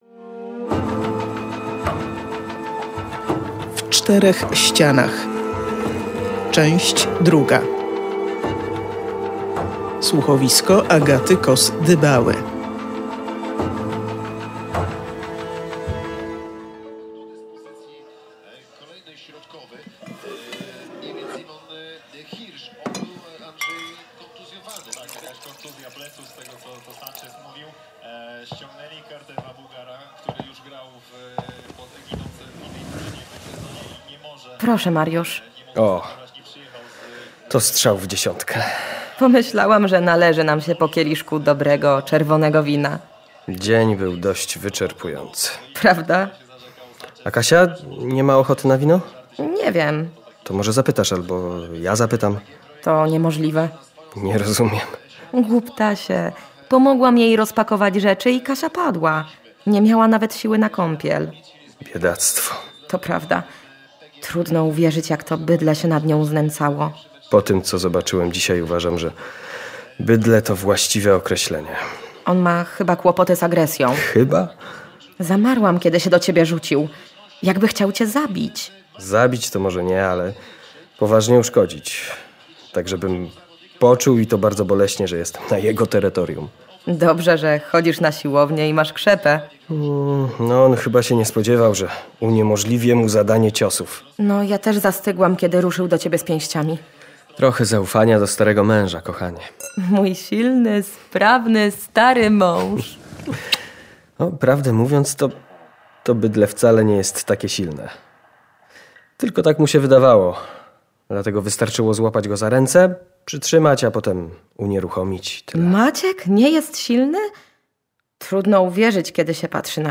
Czy otrzyma ją bohaterka słuchowiska "W czterech ścianach"?